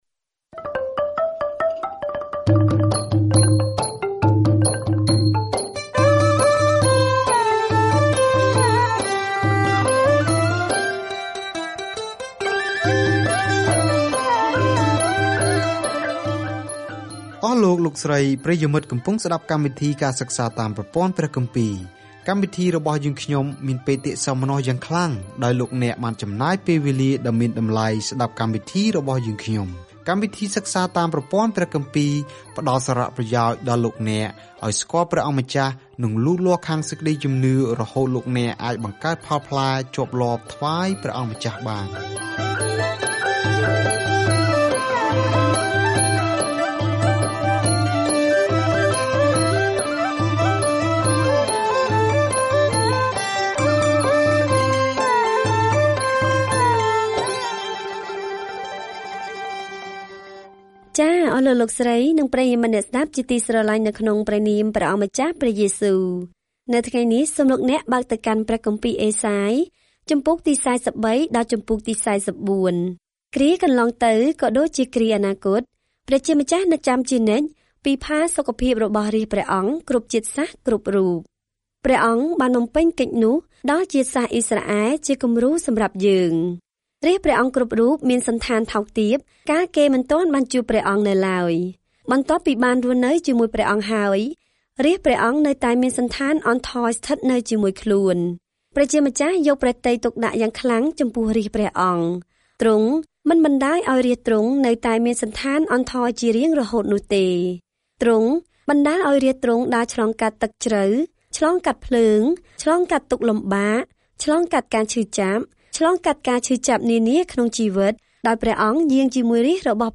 ការធ្វើដំណើរជារៀងរាល់ថ្ងៃតាមរយៈអេសាយ ពេលអ្នកស្តាប់ការសិក្សាជាសំឡេង ហើយអានខគម្ពីរដែលបានជ្រើសរើសពីព្រះបន្ទូលរបស់ព្រះ។